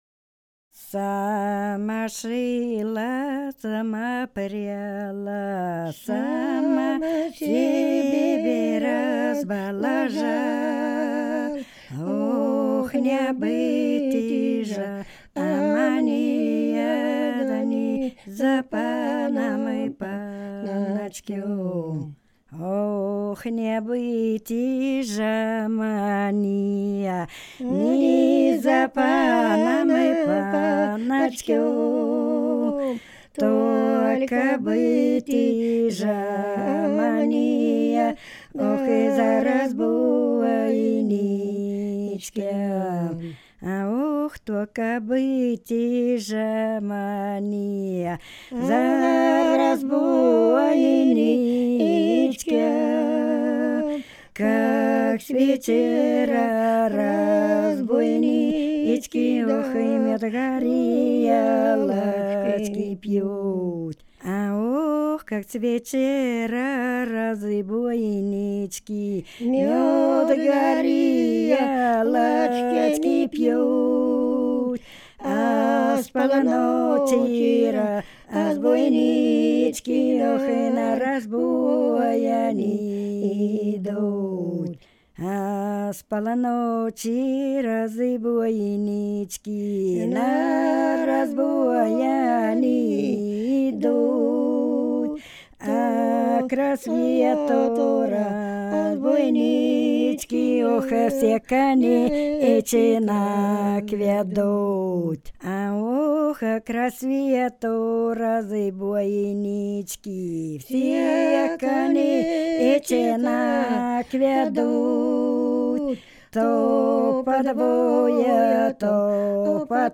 Ансамбль села Хмелевого Белгородской области Сама шила, сама пряла (бытовая баллада «Жена разбойника»; в исполнении дуэта)